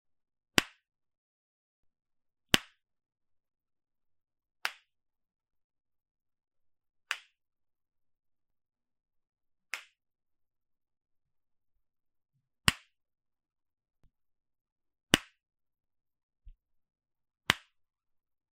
Tổng hợp tiếng Đóng/ mở hộp đựng Tai nghe AirPods
Thể loại: Tiếng đồ công nghệ
Description: Tiếng đóng mở hộp đựng tai nghe AirPods từ nhiều khoảng cách khác nhau... Airpod case open and close sound effect from different distances...
tong-hop-tieng-dong-mo-hop-dung-tai-nghe-airpods-www_tiengdong_com.mp3